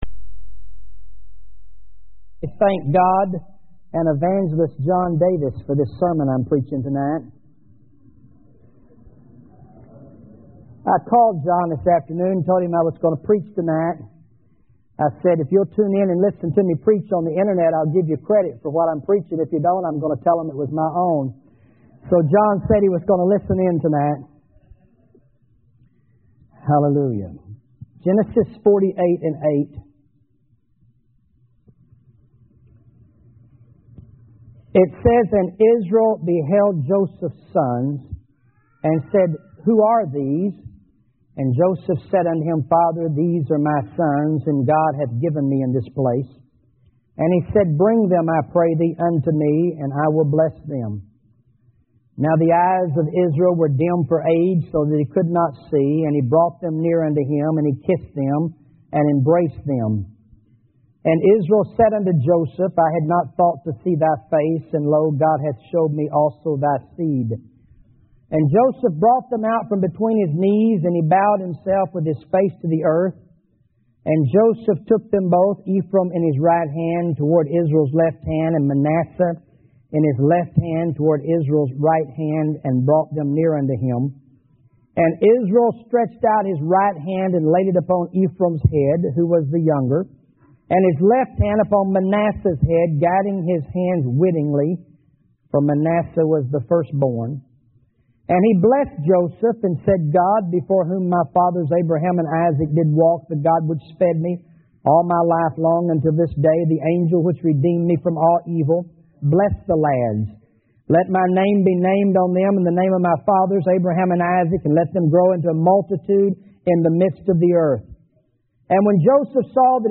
sermongodcrossed.mp3